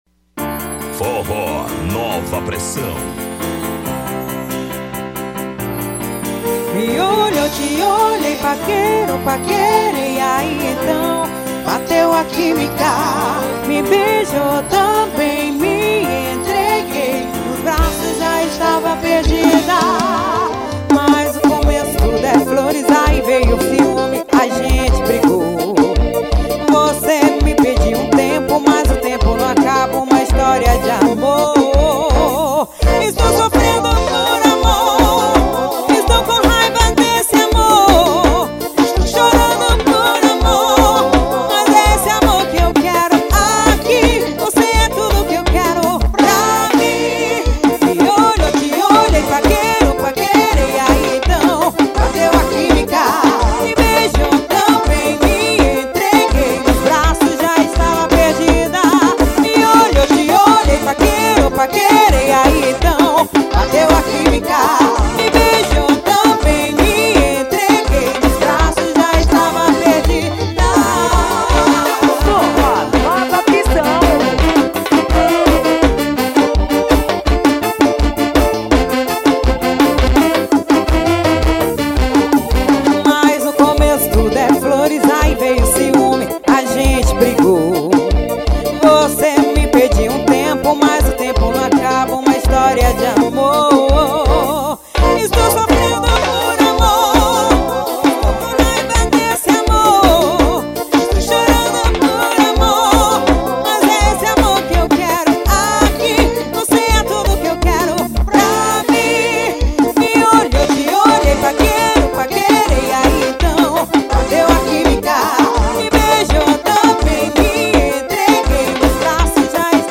forró pegado.